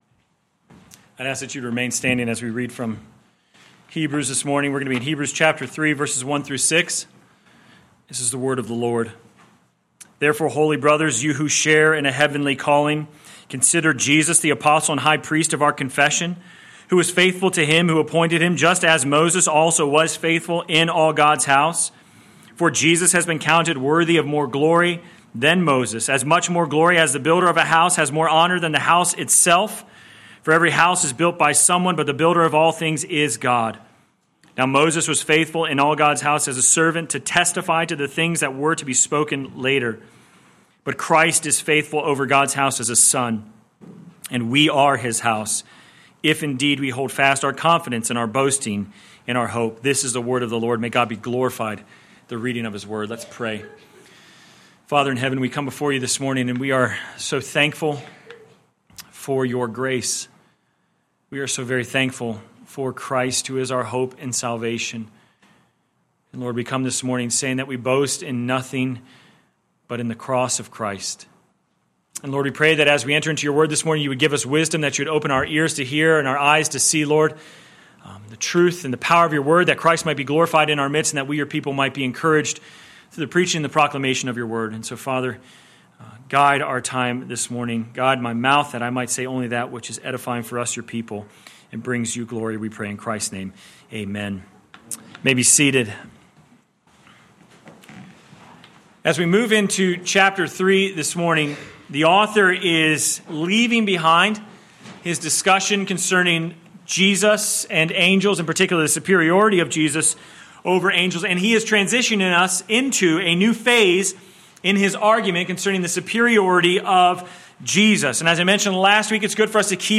Sermon Text: Hebrews 3:1-6 First Reading: 2 Samuel 7:1-14 Second Reading: 1 Peter 2:1-12